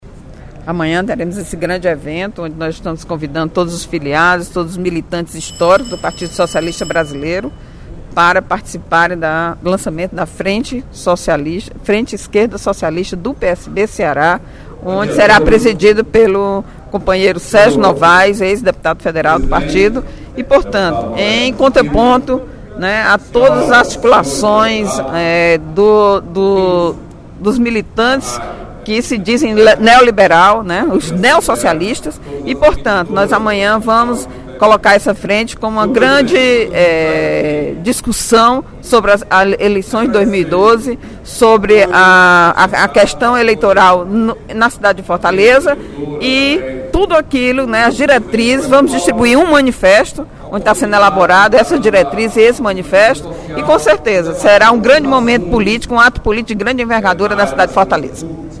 A deputada Eliane Novais (PSB) foi à tribuna na sessão plenária desta sexta-feira (25/05), para anunciar o lançamento da Frente de Esquerda Socialista, neste sábado (26/05), a partir das 9h, em frente a Casa José de Alencar.